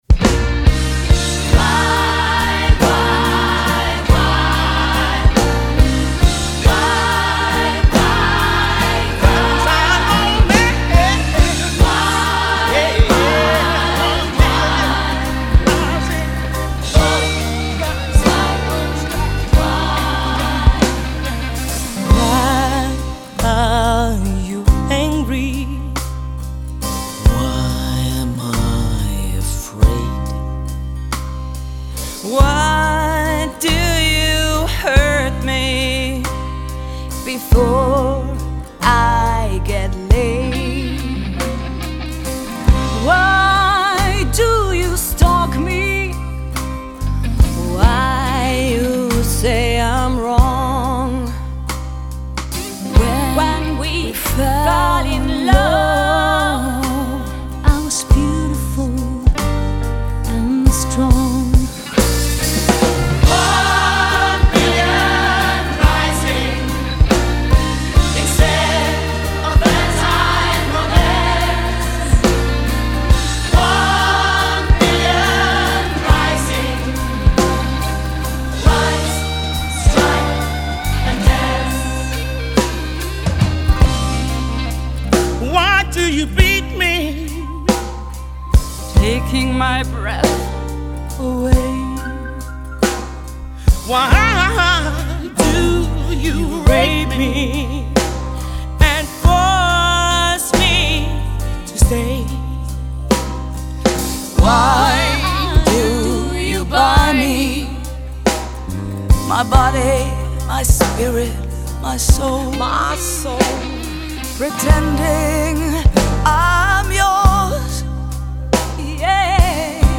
mit Unterstützung vieler Musikerinnen